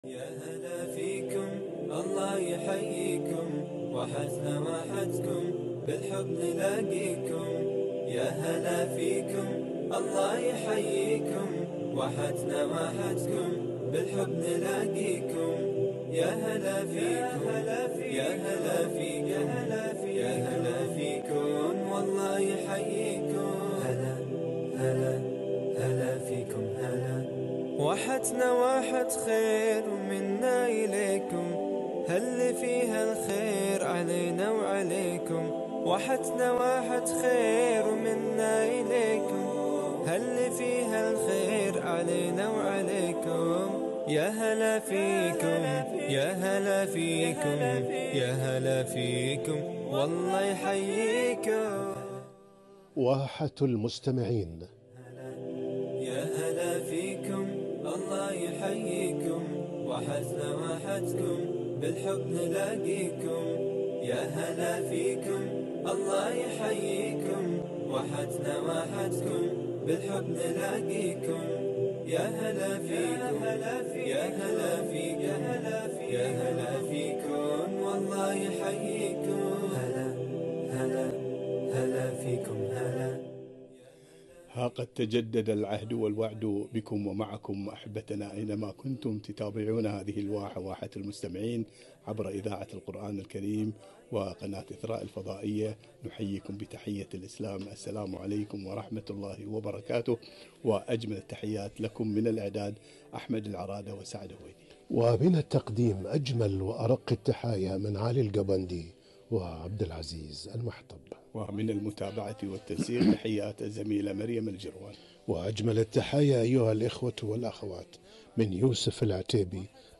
مكانة المسجد في الإسلام - لقاء إذاعي